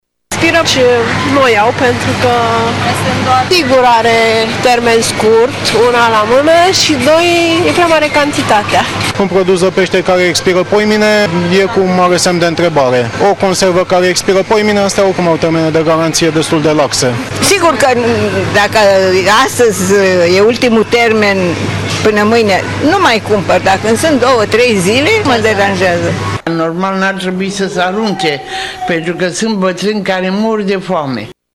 Tîrgumureşenii sunt atenţi la data de expirare. Unii cumpără anumite produse chiar dacă mai au cîteva zile de valabilitate, alţii spun însă, că o astfel de măsură ar fi binevenită pentru că sunt oameni care mor de foame: